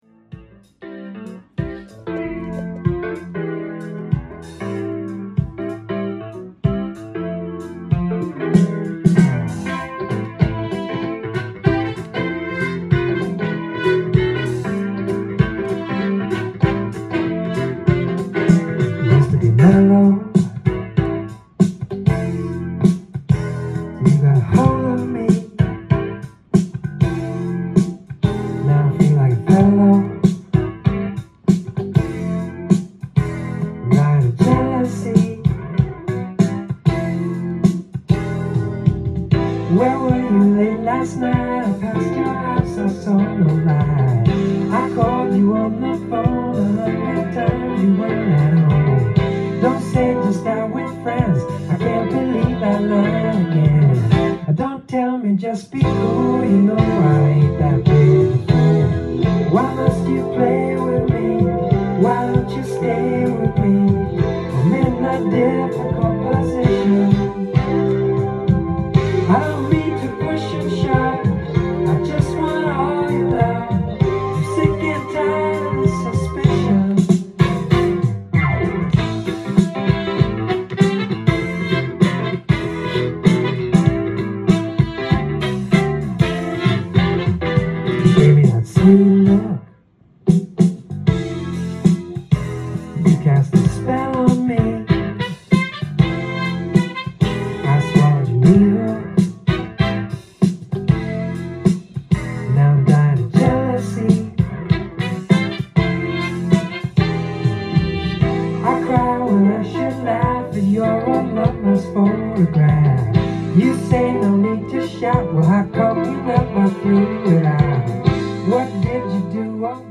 LP
店頭で録音した音源の為、多少の外部音や音質の悪さはございますが、サンプルとしてご視聴ください。
都会的でジャジーな魔術的アレンジが冴え渡るAOR〜ライトメロウ不朽の名作！！